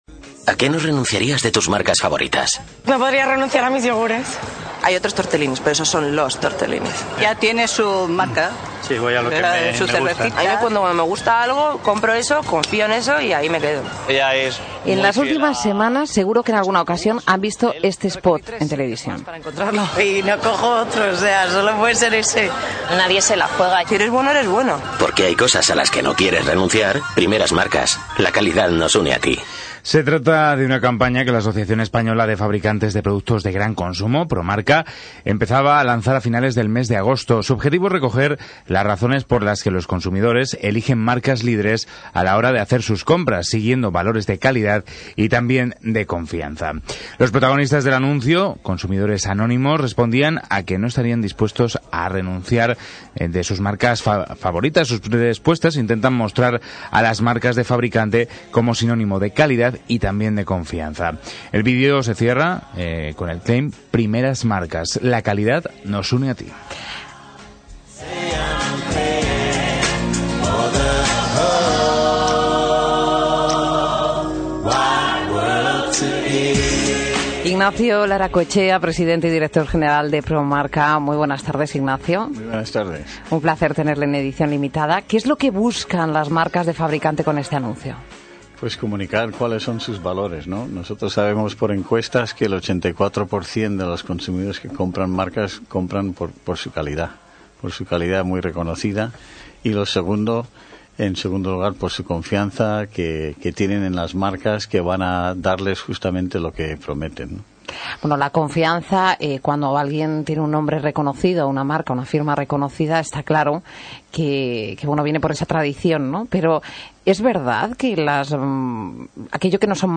Gestiona Radio. Entrevista